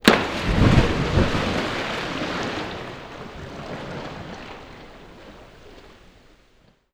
SPLASH_Deep_03_mono.wav